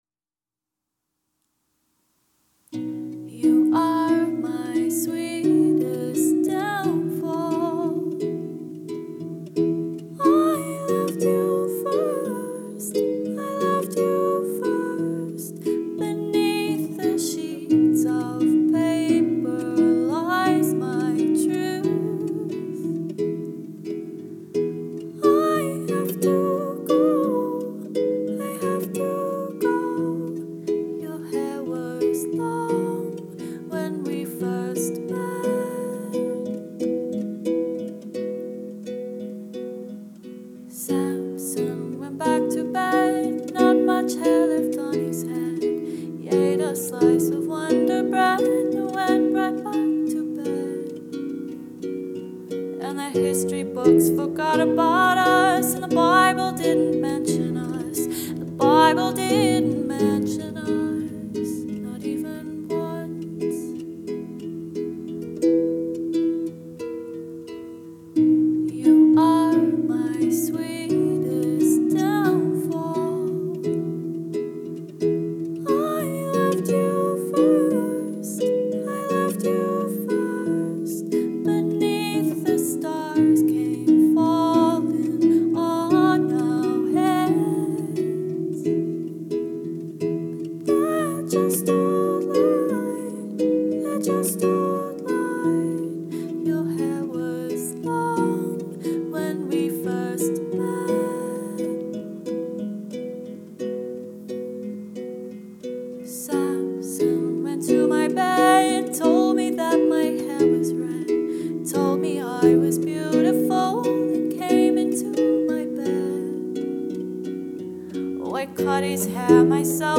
Harpist & Vocalist
Ambient, Impactful, Luxurious…
Band | Duo | Folk | Jazz | Pop | Solo